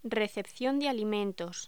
Locución: Recepción de alimentos
voz
Sonidos: Voz humana